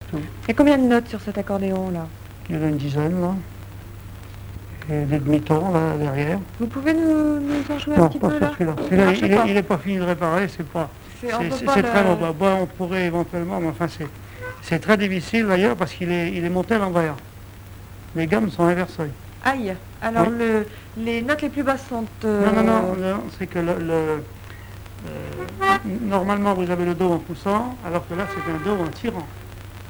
accordéon(s), accordéoniste
Musique, bal, émission de radio
Témoignage